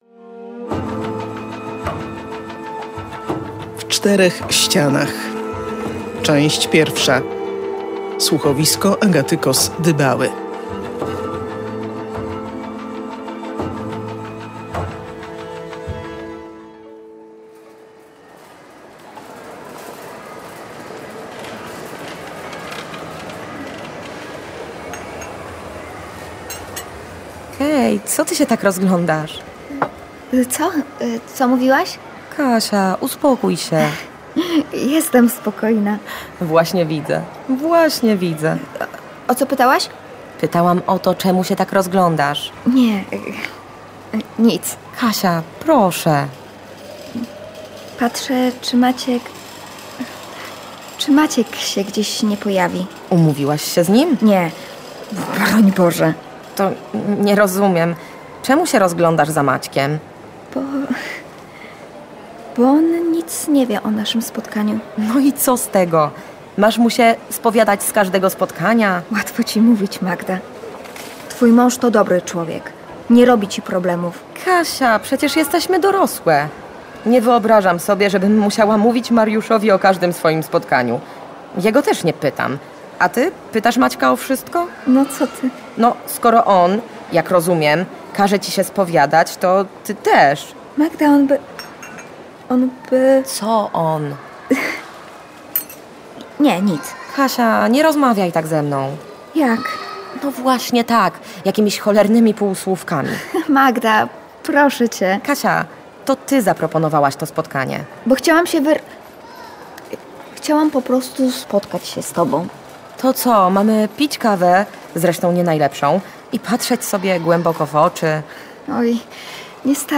„W czterech ścianach” to także tytuł tego słuchowiska kryminalnego.